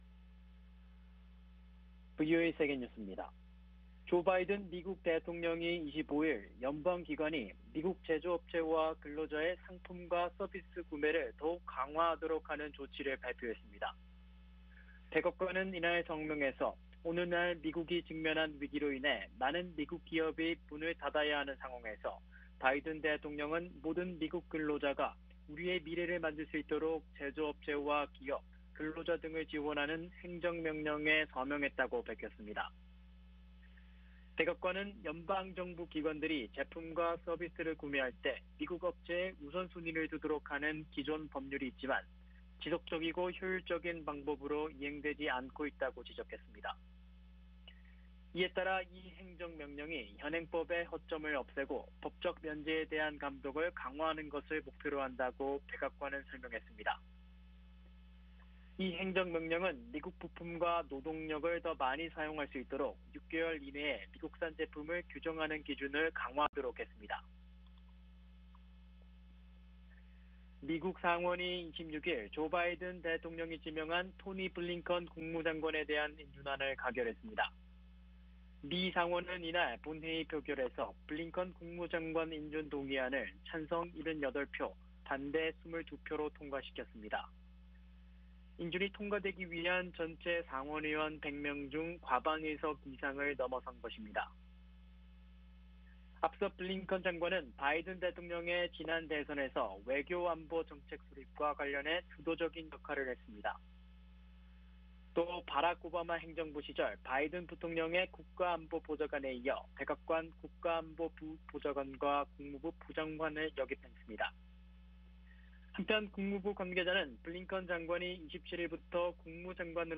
VOA 한국어 아침 뉴스 프로그램 '워싱턴 뉴스 광장' 2021년 1월 27일 방송입니다. 미국 재무부는 트럼프 대통령 재임 4년 동안 240차례가 넘는 대북 제재 조치를 취했다며, 궁극적인 목표는 한반도의 비핵화라고 밝혔습니다. 미국의 조 바이든 행정부가 과거 북한 등 한반도 문제를 깊이 다뤄온 정통 관료들을 고위직에 잇따라 발탁하고 있습니다. 조 바이든 행정부가 북한과의 협상을 재개한다면 북한의 점진적 비핵화와 ‘빅딜’ 중 하나의 선택에 직면할 것이라고, 미국 의회조사국이 전망했습니다.